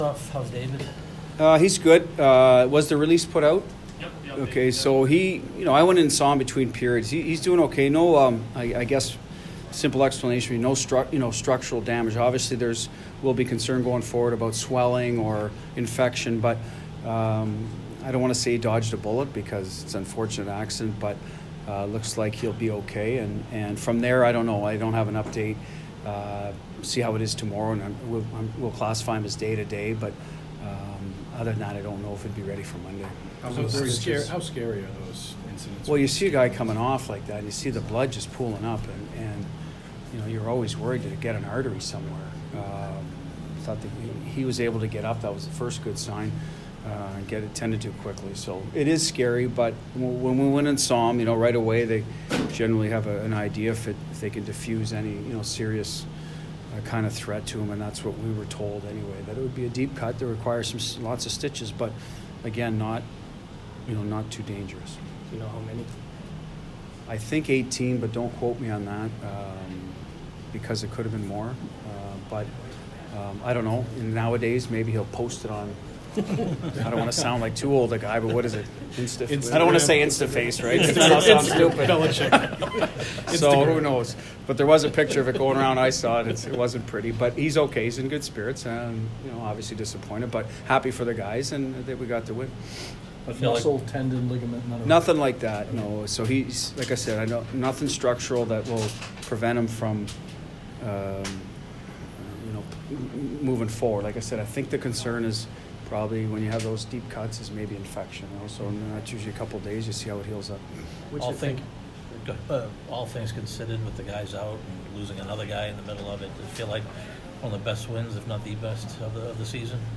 Bruins Head Coach Bruce Cassidy post-game 3/17